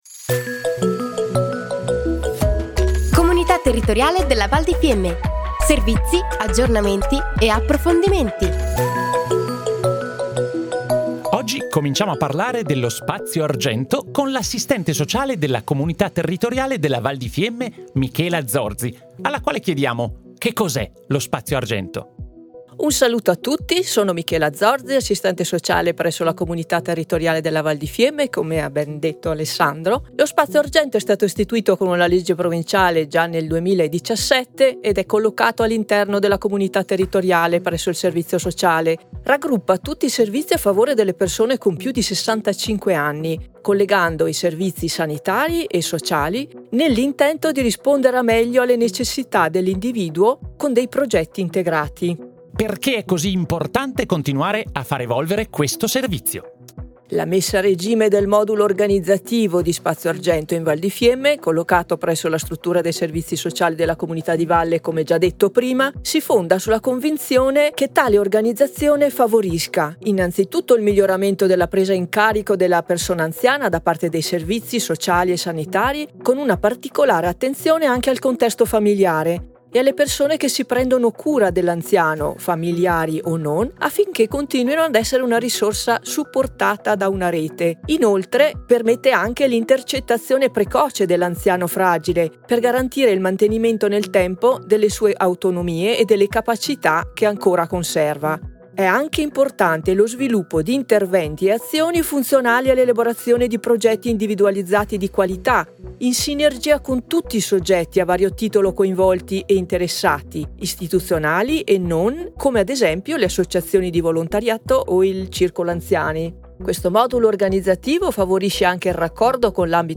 Puntata 06 - Spazio Argento - intervista